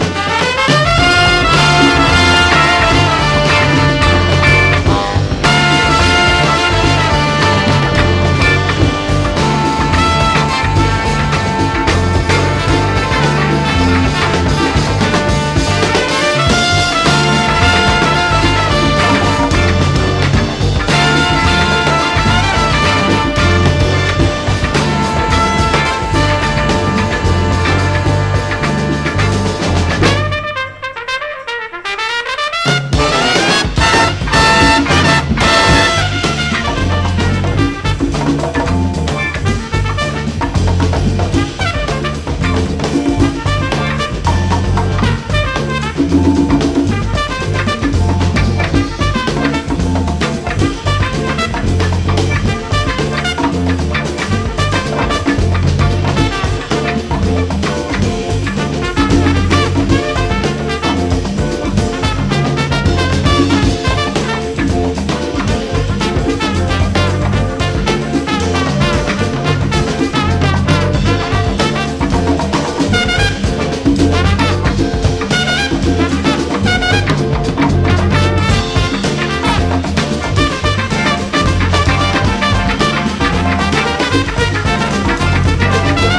ユーロ・ジャズ的なコンテンポラリー・サウンドを聴かせます。